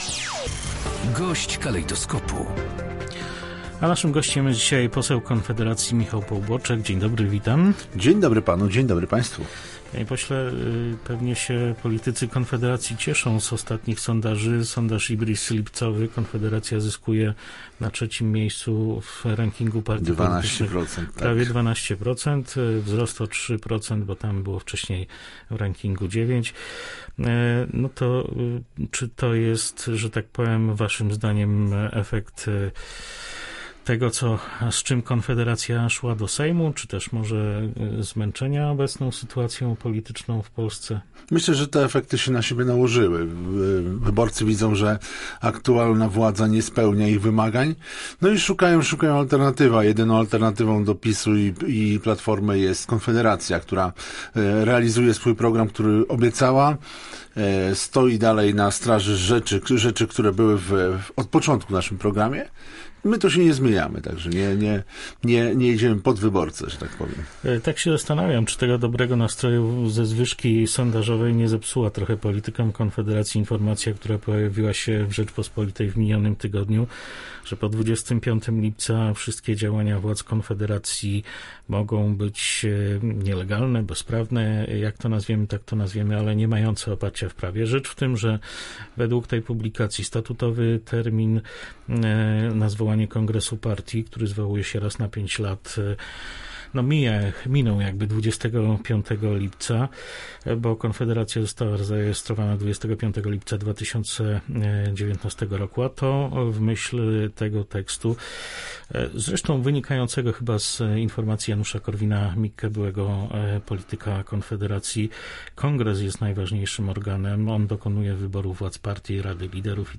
Obecne przepisy są zbyt skomplikowane – uważa nasz gość.